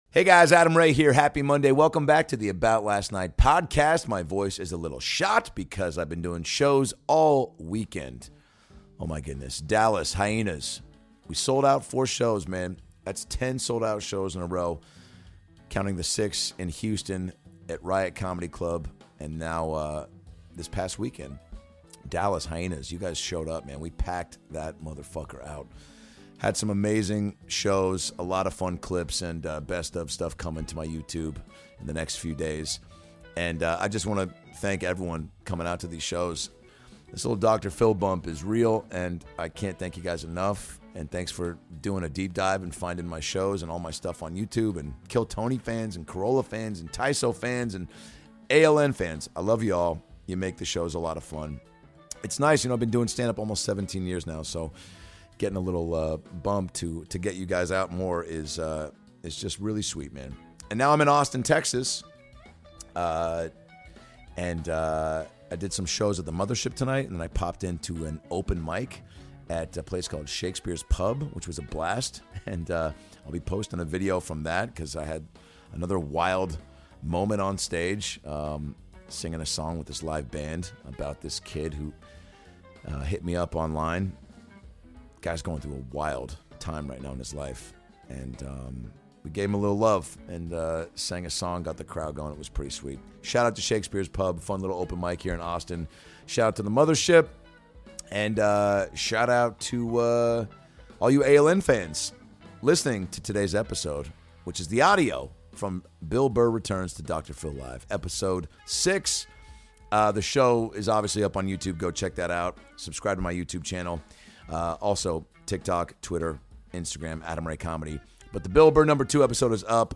#746 - Dr. Phil Live! Ft. Bill Burr (He's Back!)
Adam Ray as Dr. Phil